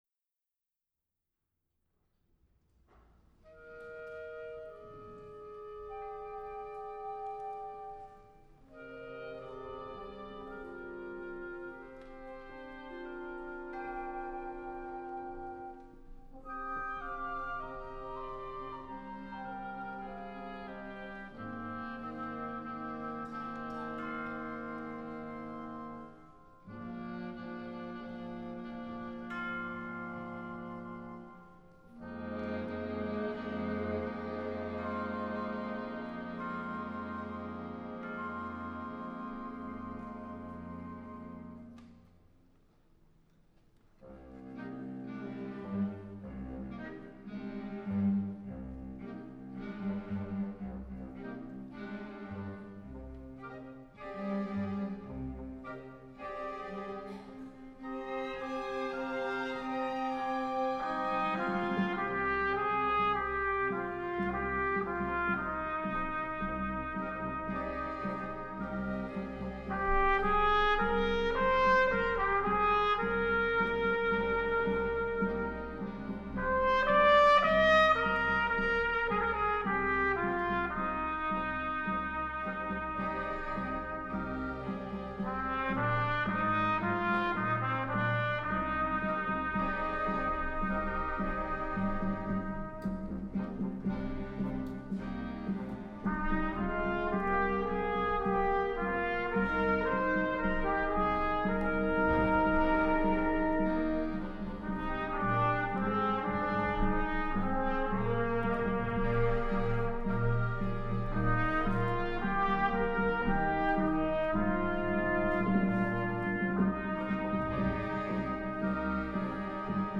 for readers, actors, and chamber orchestra
a performance of the original orchestra version of this work